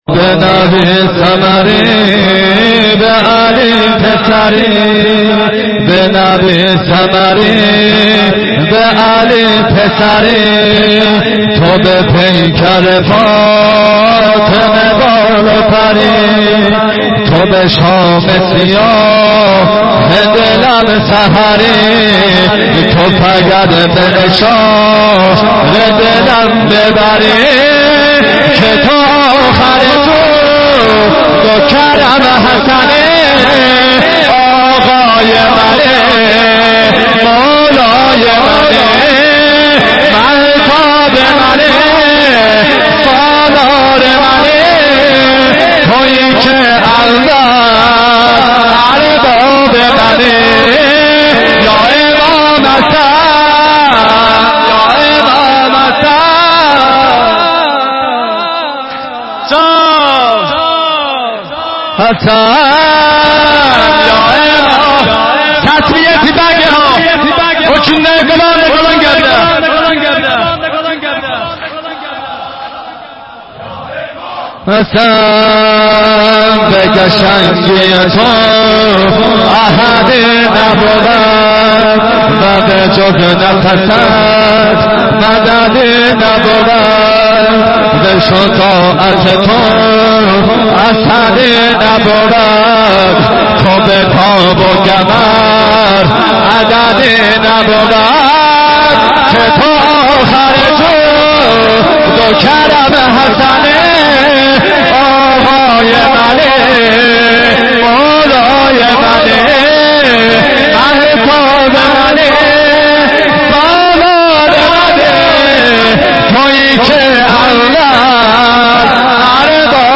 مداحی دوم